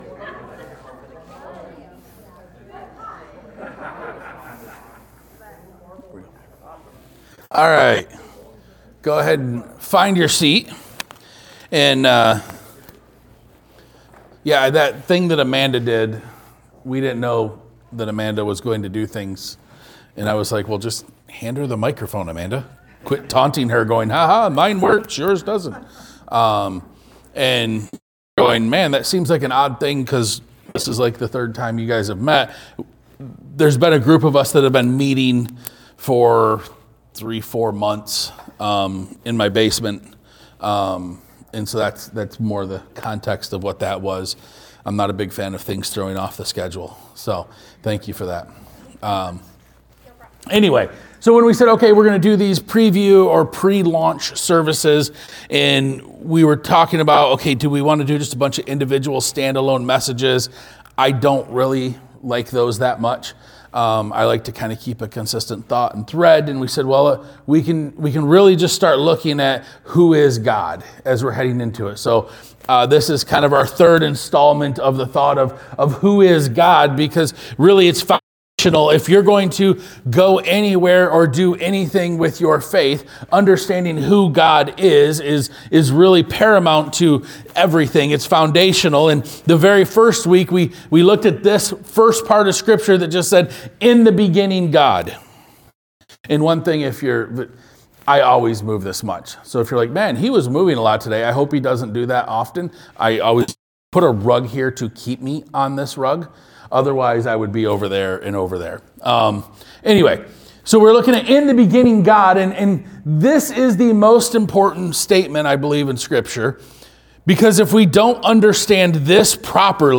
Sermon 12-21.mp3